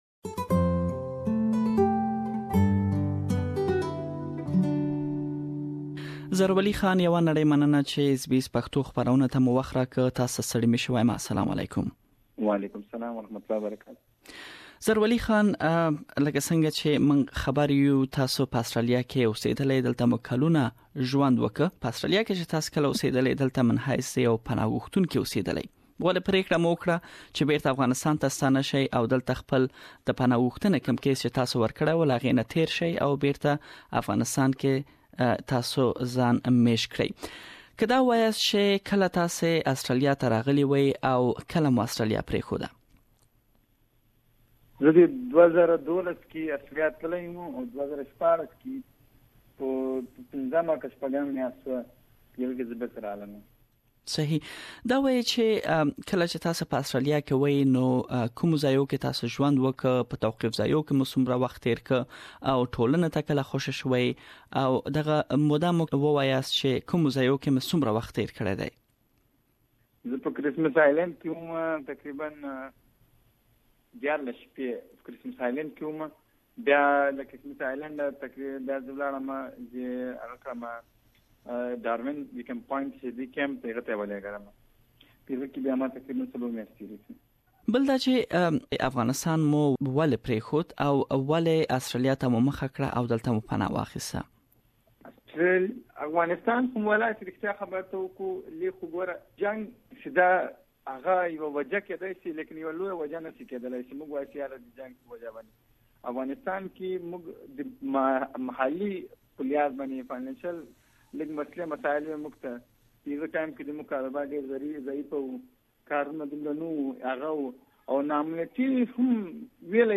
We have interviewed him on his journey and asked about his present life in Afghanistan. Please listen to the first part of his interview here.